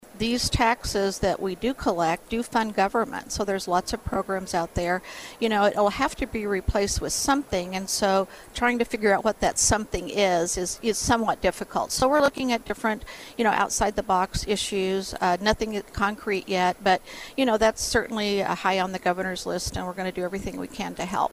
Missouri Department of Revenue Director Trish Vincent says the plan remains in its early stages and is currently under review.